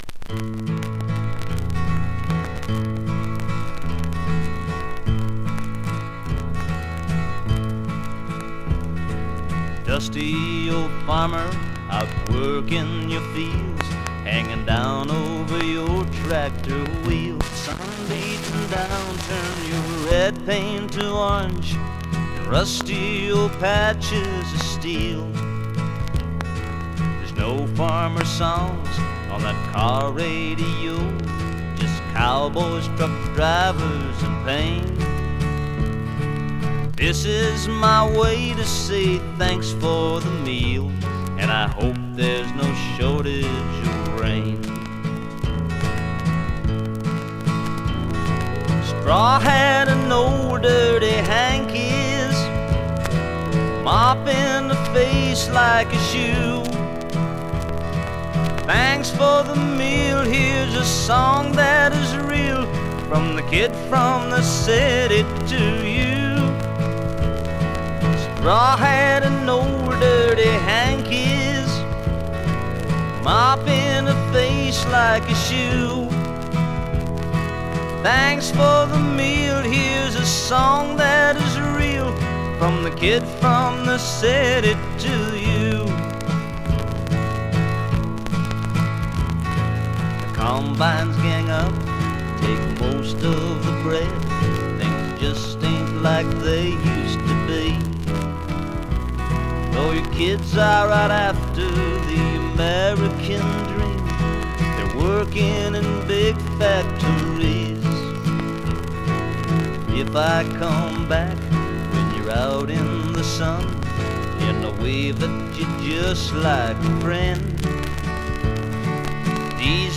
Vinyl quality is the absolute shits here.
The songs skipped worse afterwards.